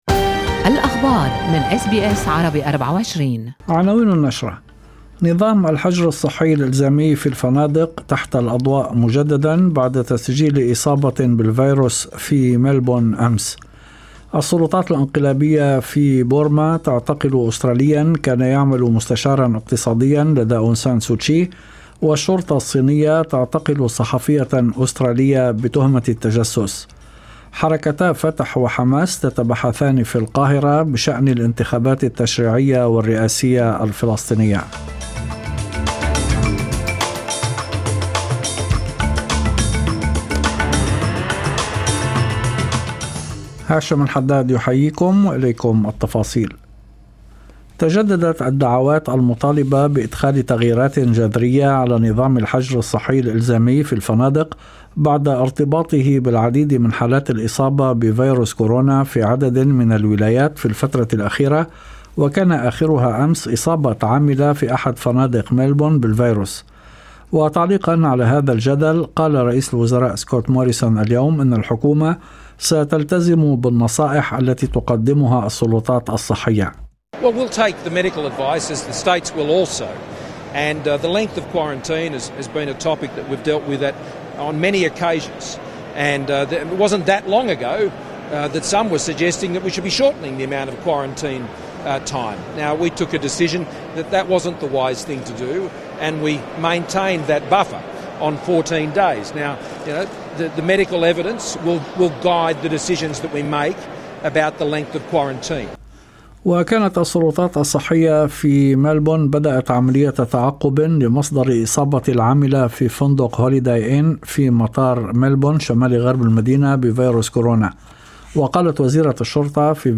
نشرة أخبار المساء 8/2/2021